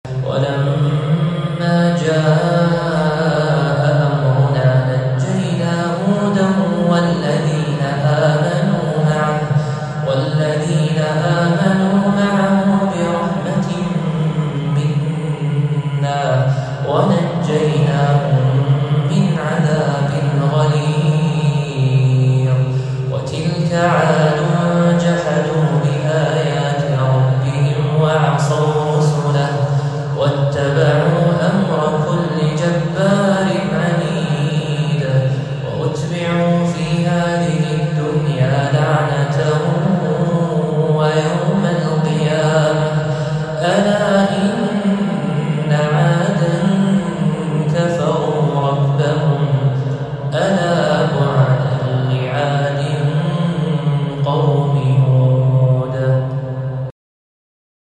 تلاوة من سورة هود